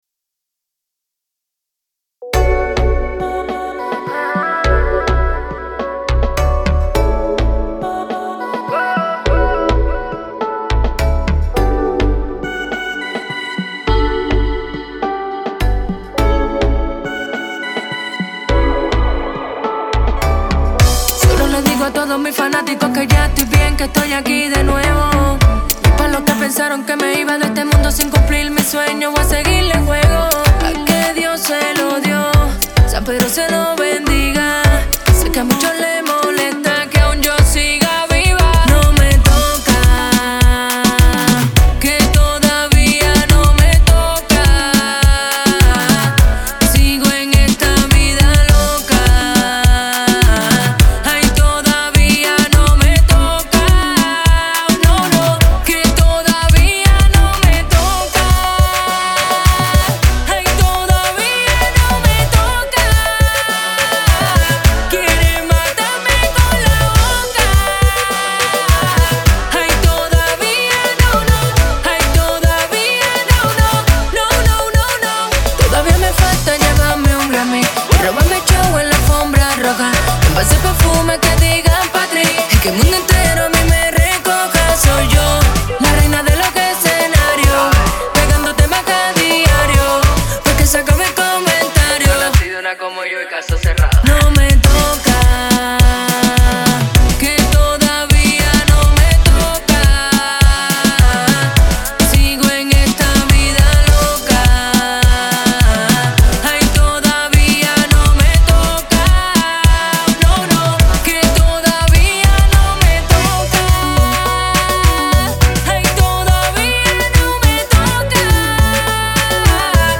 это зажигательная песня в жанре латинского попа